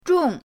zhong4.mp3